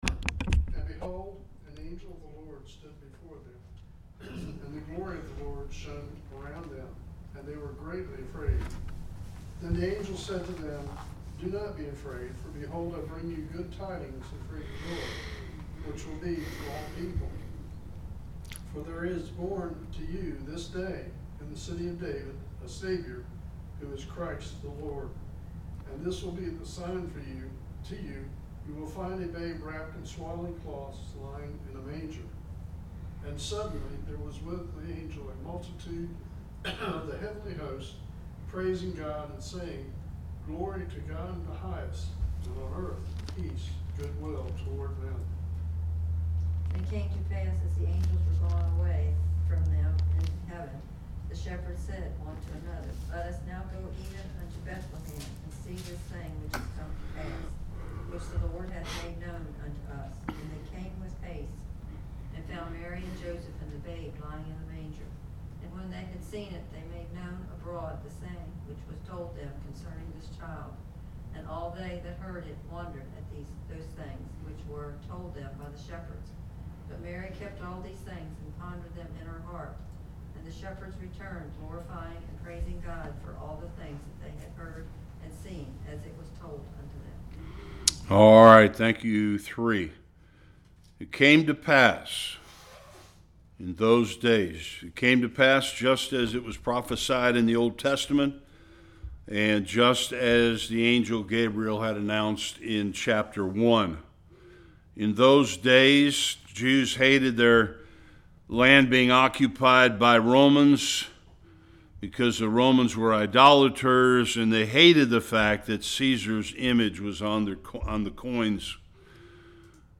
1-21 Service Type: Bible Study The miracle of the birth of Christ.